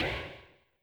HeavenStudioPlus/Assets/Resources/Sfx/games/rockers/bendUp.wav at 5e63a5898a87d96abe75babd1f4fdf83ef6d620c
bendUp.wav